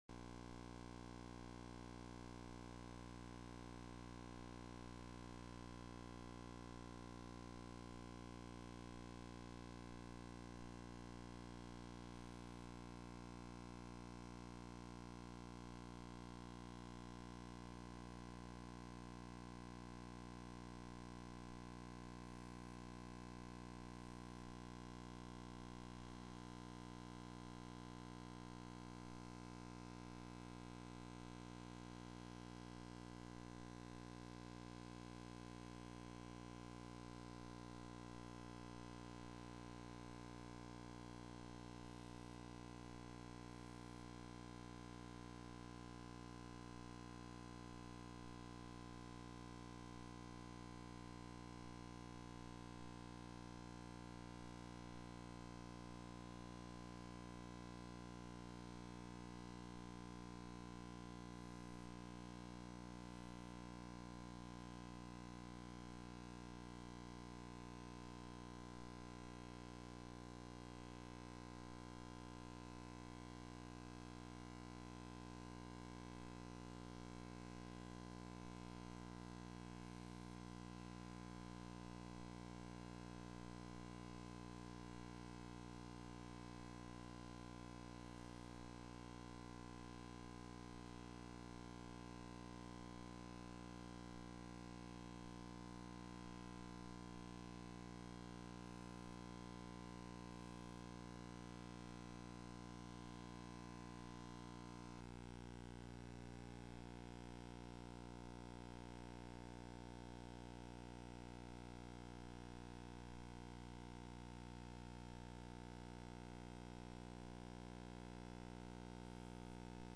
Sermons Archive - Page 54 of 63 - Rocky Mountain Alliance Church